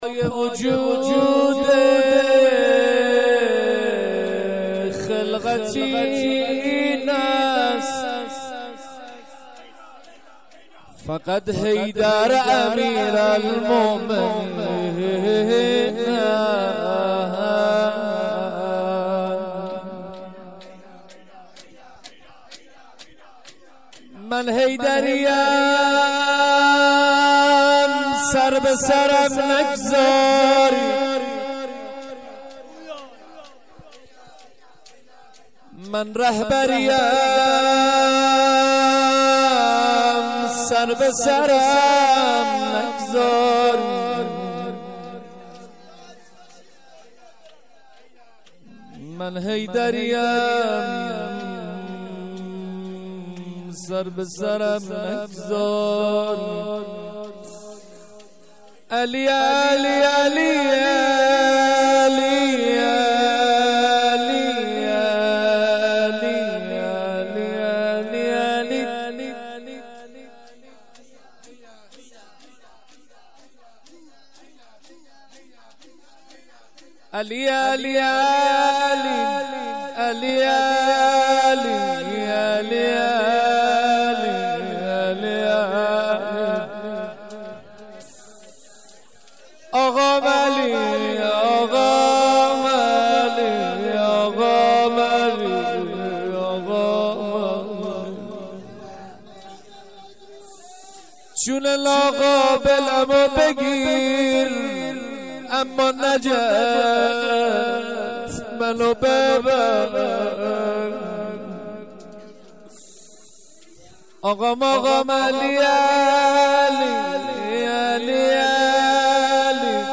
روضه آخر جلسه به همراه مدح حضرت علی(ع)
هیات العباس